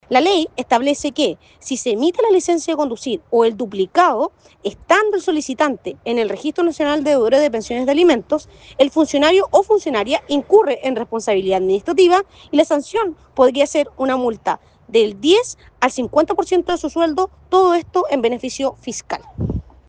Al respecto, la seremi de Justicia y Derechos Humanos de Valparaíso, Paula Gutiérrez, explicó la importancia de la medida y detalló las multas que arriesgan los funcionarios que “incurran en esta responsabilidad administrativa”.